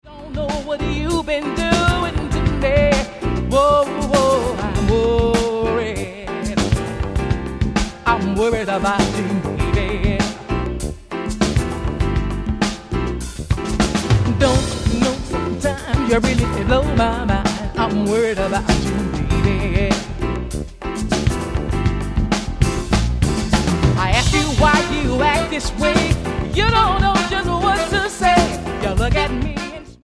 piano
keyboards
acoustic bass
electric bass
drums
tenor sax
flugelhorn
By Genre Jazz